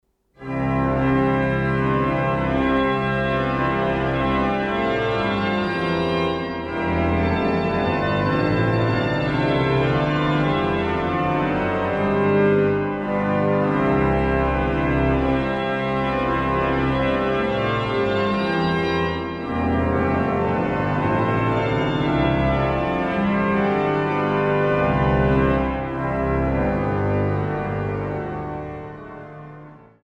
Wäldner-Orgel, Dom zu Halle